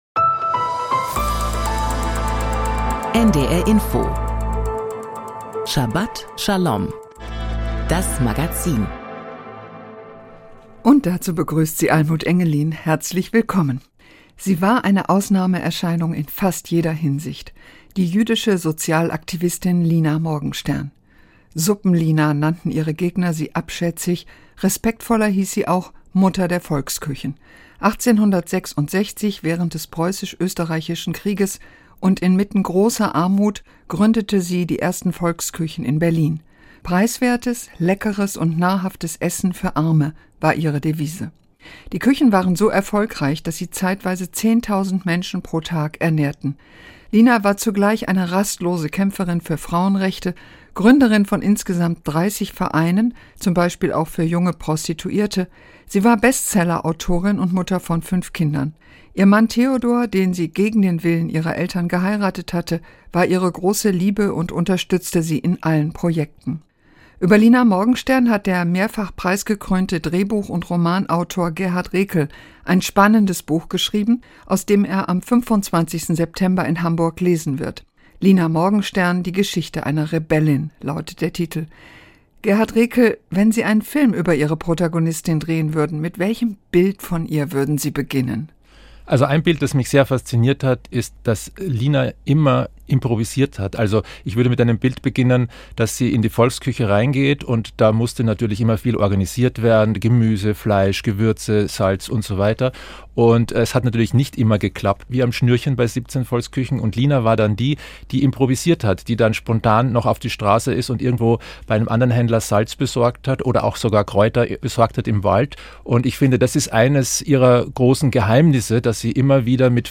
Die Themen der Sendung: Erfinderin der Volksküchen Interview